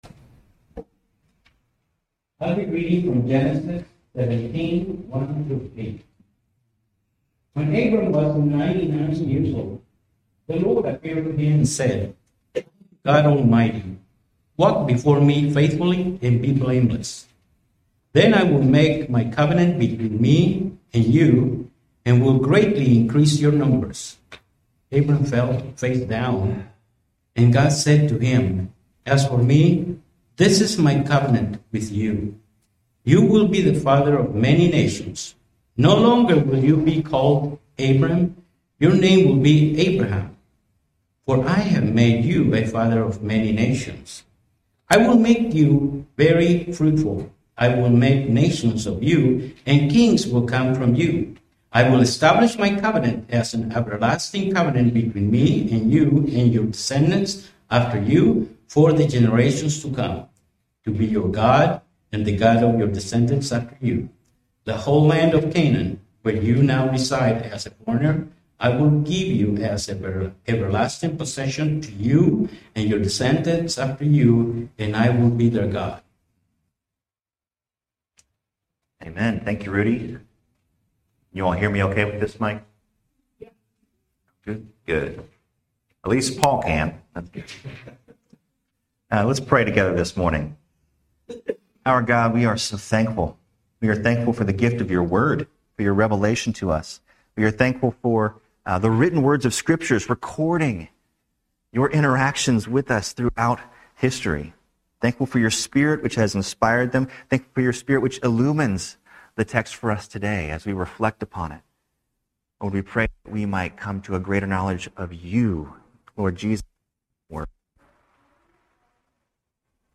Sermons at Smoky Row Brethren Church